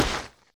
JumpDown.mp3